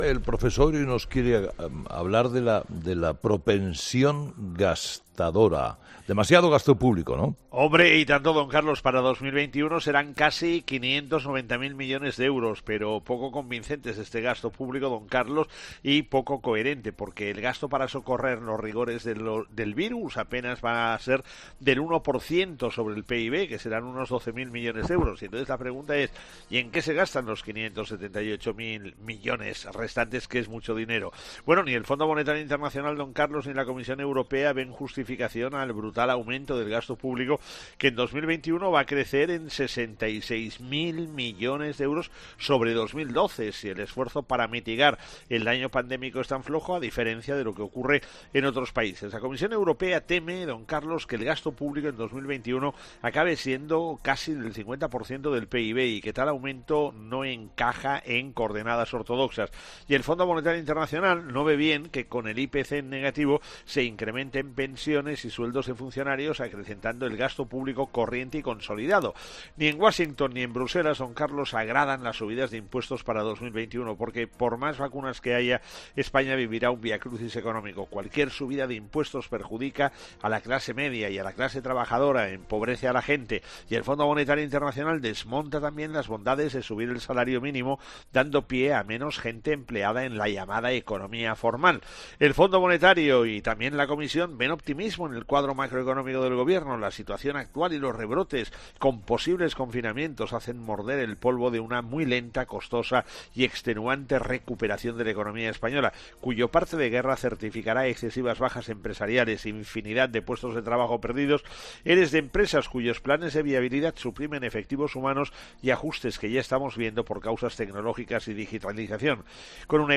El profesor José María Gay de Liébana analiza en 'Herrera en COPE’ las claves económicas del día.